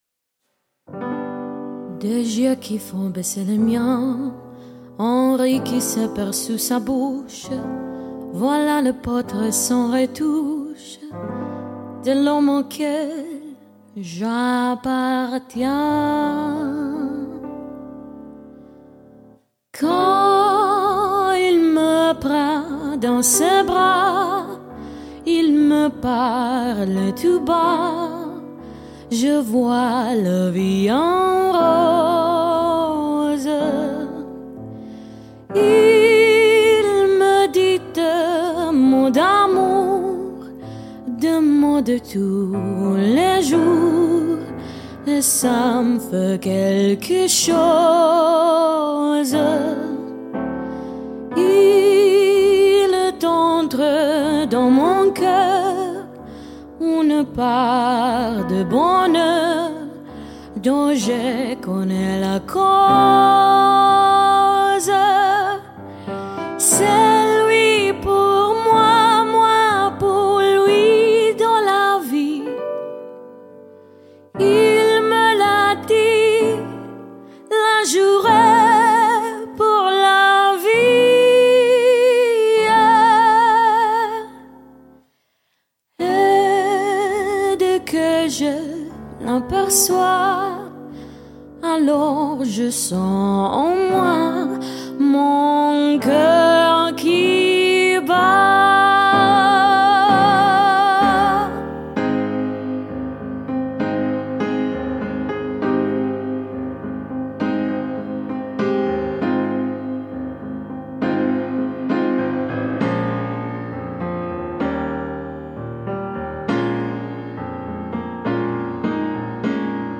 Sensational, stylish jazz/pop band.
• Unique & original jazz arrangements of popular songs
Female Vocals, Piano, Bass, Drums